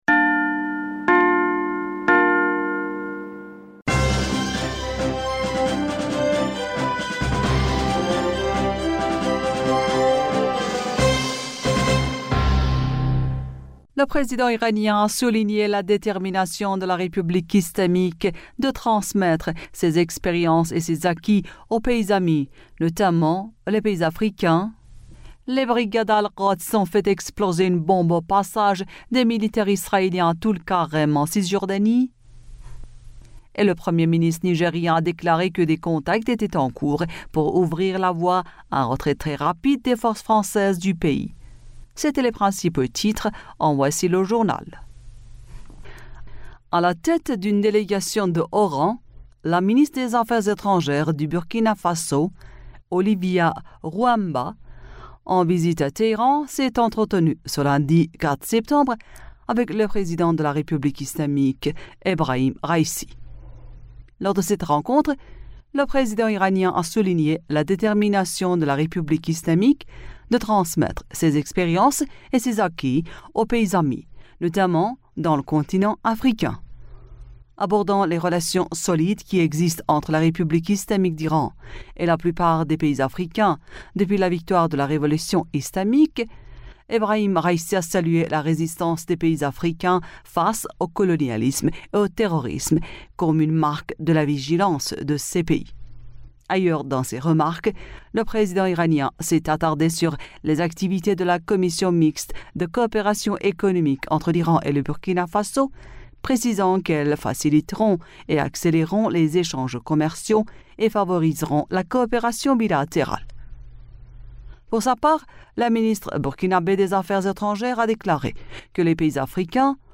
Bulletin d'information du 05 Septembre 2023